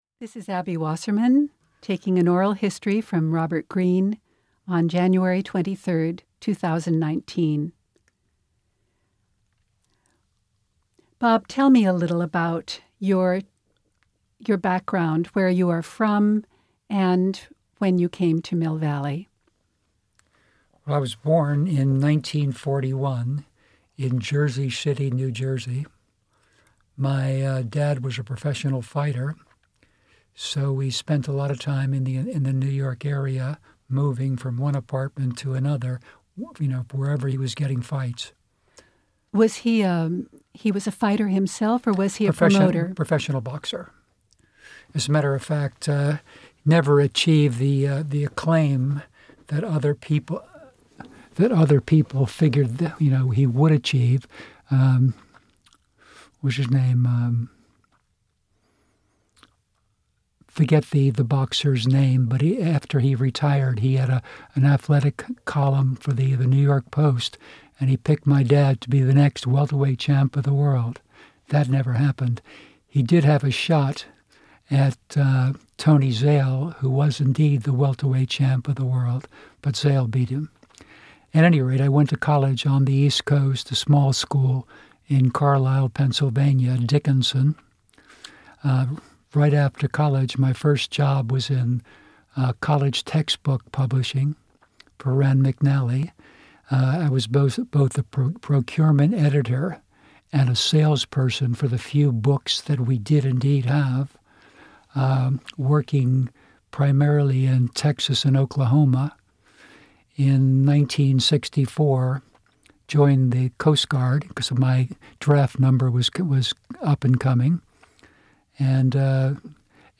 Oral history - Local business